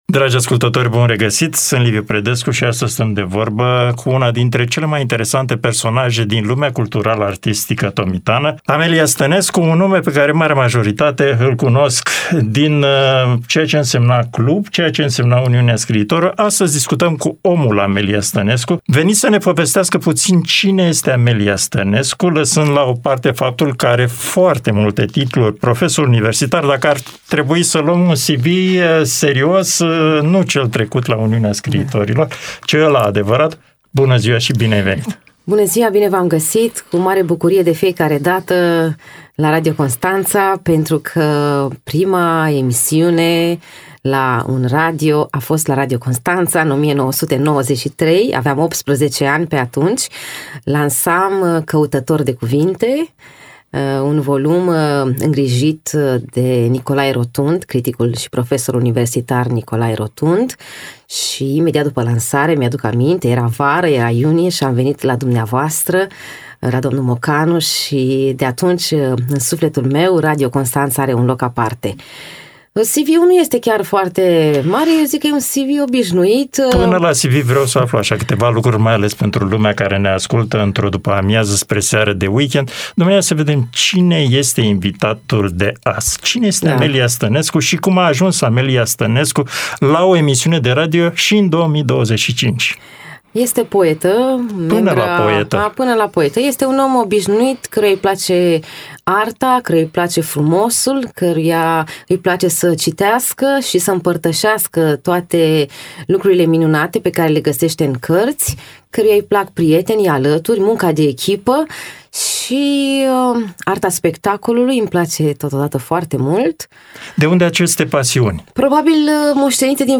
Povești din Dobrogea | Interviu